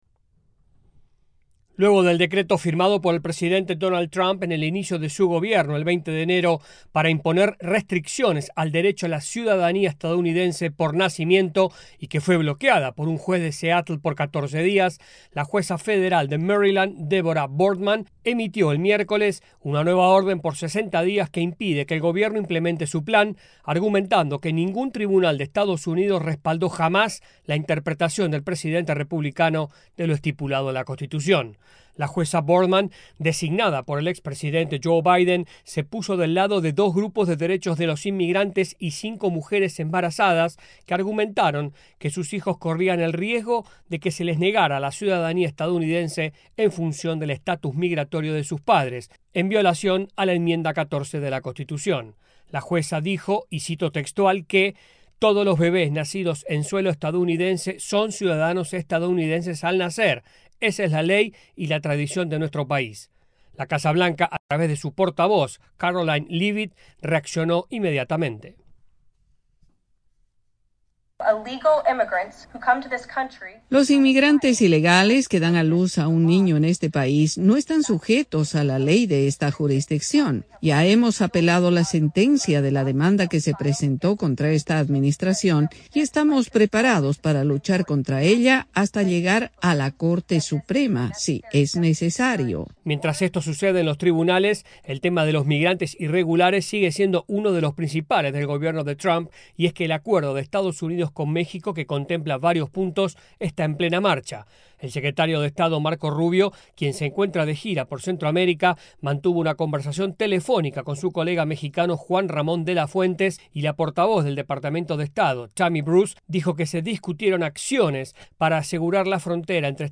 AudioNoticias
desde la Voz de América en Washington, DC.